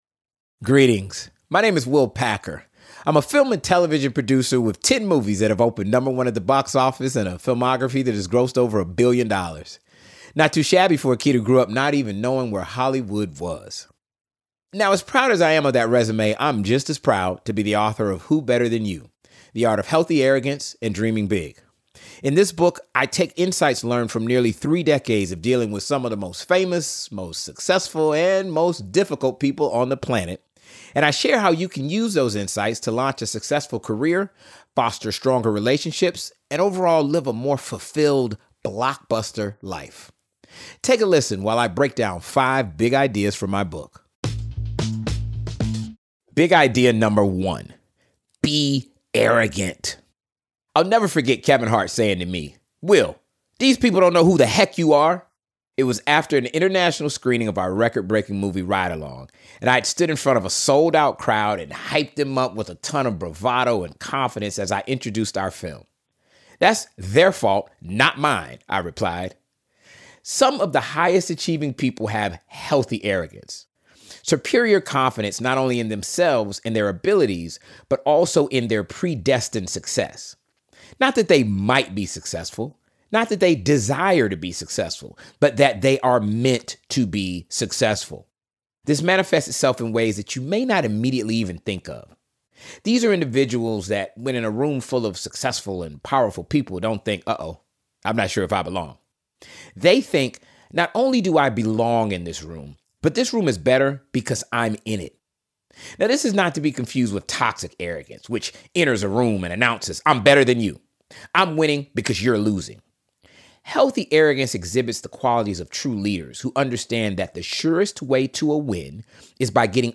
Listen to the audio version—read by Will himself—in the Next Big Idea App.